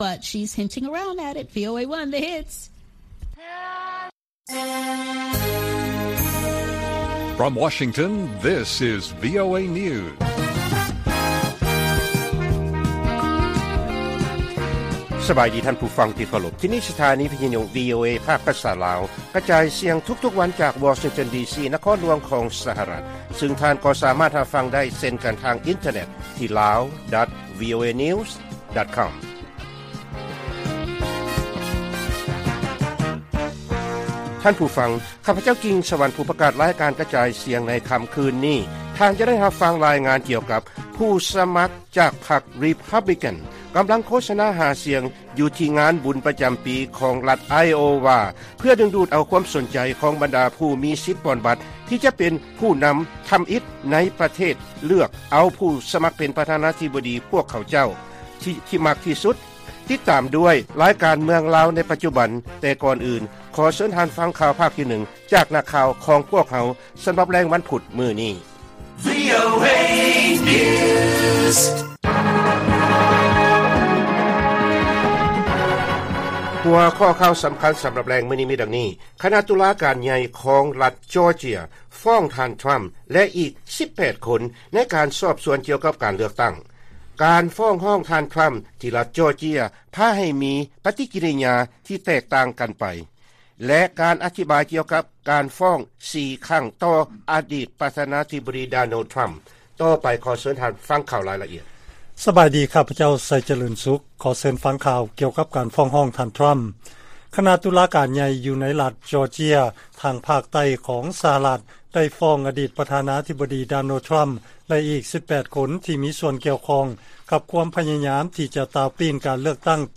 ລາຍການກະຈາຍສຽງຂອງວີໂອເອ ລາວ: ຄະນະຕຸລາການໃຫຍ່ ຂອງລັດຈໍເຈຍ ຟ້ອງທ່ານທຣຳ ແລະອີກ 18 ຄົນ ໃນການສອບສວນກ່ຽວກັບການເລືອກຕັ້ງ